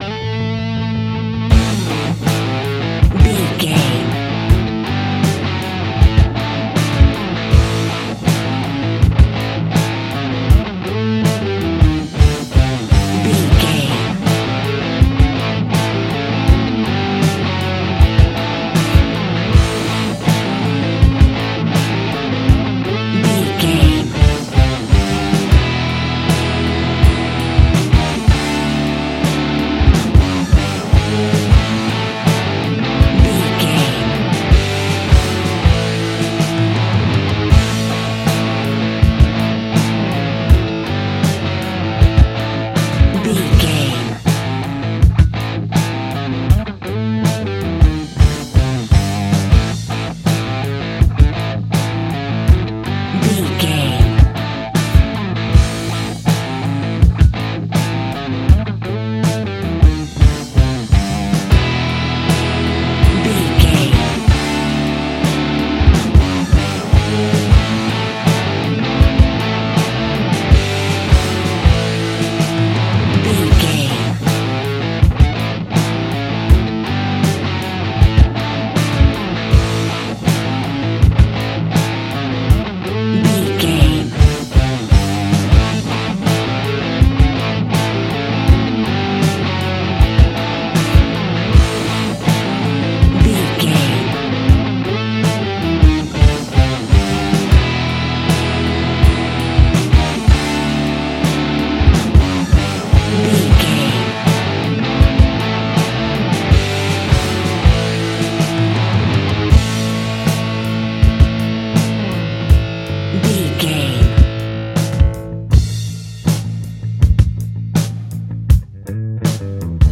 Ionian/Major
groovy
powerful
electric guitar
bass guitar
drums
organ